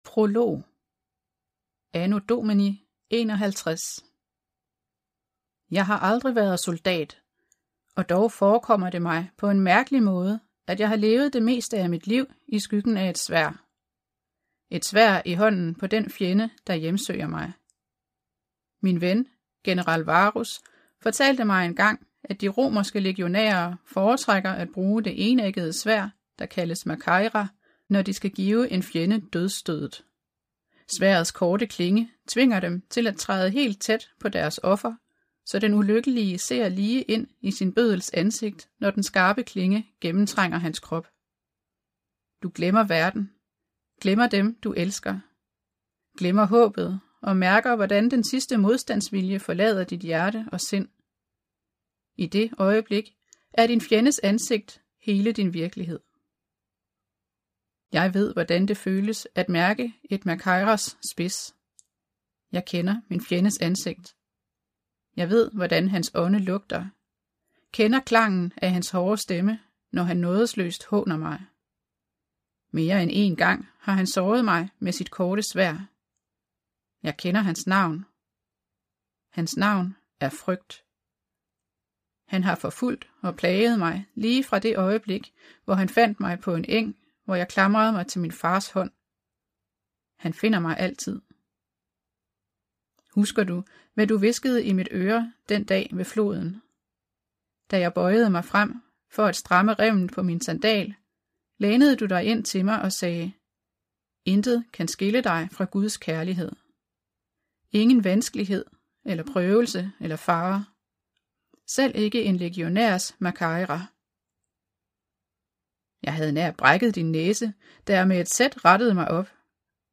Hør et uddrag af Englenes brød Englenes brød Format MP3 Forfatter Tessa Afshar Bog Lydbog 249,95 kr.